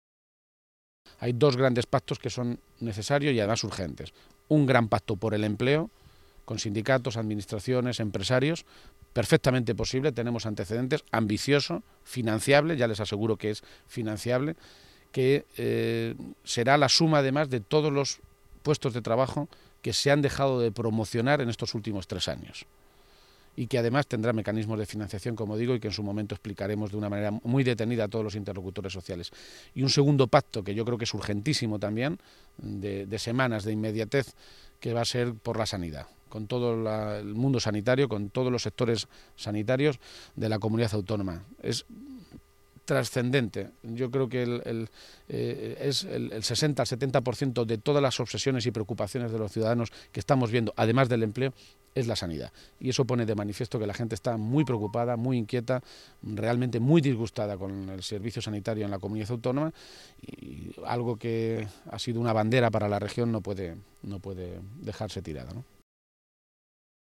García-Page ha avanzado esta noticia esta mañana, en Toledo, a preguntas de los medios de comunicación, y ha detallado que se trata “de hablar con todo el mundo, de que entre todos tiremos del carro. Se trata de hablar con los empresarios, los sindicatos y los trabajadores, y de consensuar con los profesionales de la Sanidad porque, sin duda, el empleo y el deterioro de la Sanidad son los dos grandes problemas de Castilla-La Mancha”.
Cortes de audio de la rueda de prensa